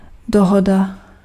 Ääntäminen
Tuntematon aksentti: IPA: /a.kɔʁ/